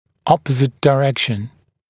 [‘ɔpəzɪt dɪ’rekʃn][‘опэзит ди’рэкшн]противоположное направление